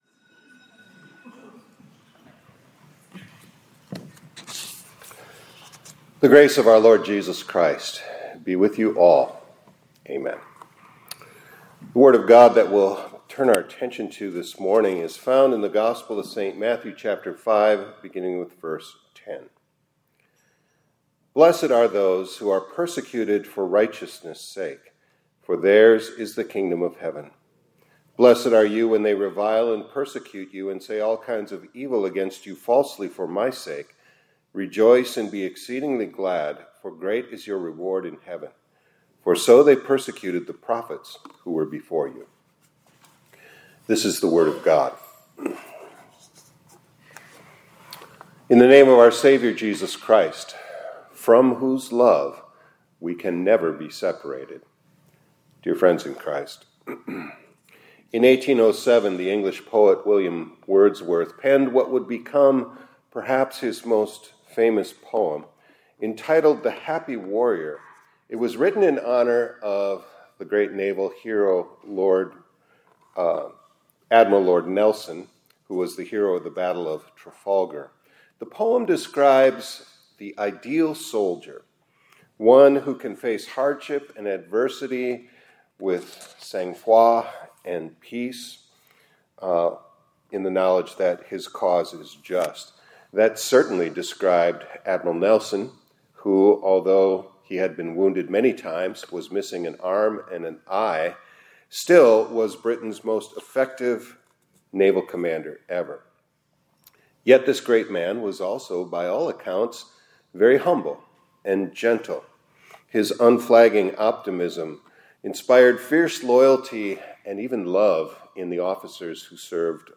2025-09-15 ILC Chapel — HAPPY WARRIORS: Fighting in a Righteous Cause